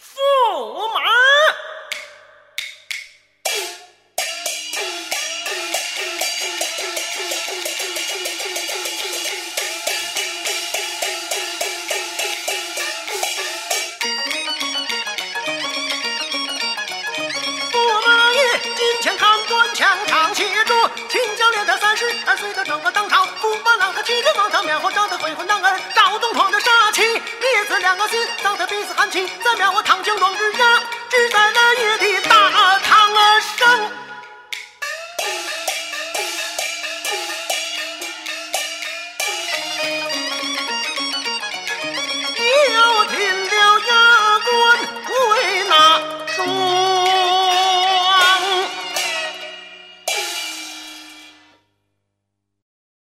京剧
［西皮导板］